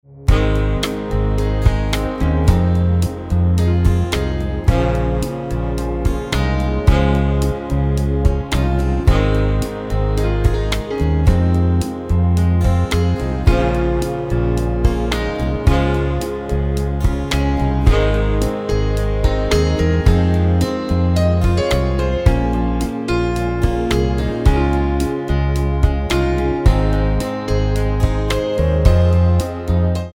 Tonart:Ab ohne Chor